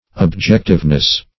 Objectiveness \Ob*jec"tive*ness\, n.